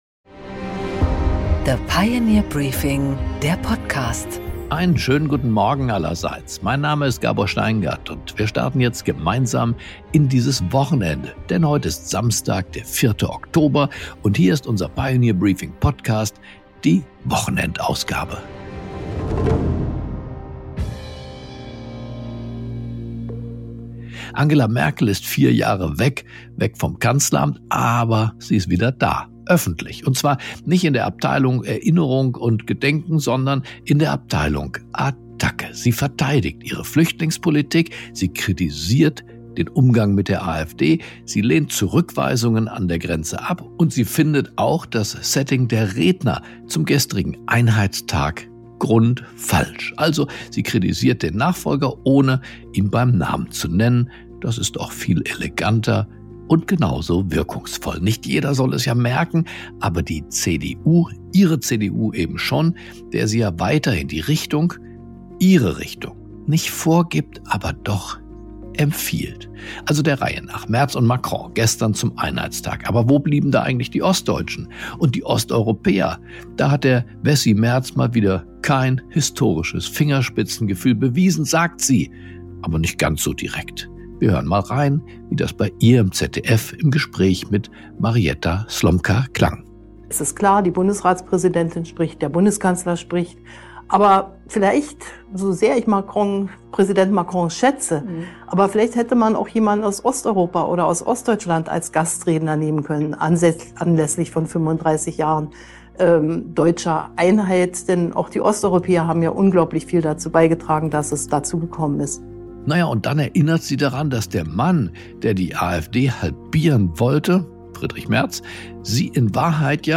Gabor Steingart präsentiert die Pioneer Briefing Weekend Edition
Star-Medienanwalt Matthias Prinz spricht über seine spektakulärsten Fälle, Journalismus und seinen folgenreichen Unfall.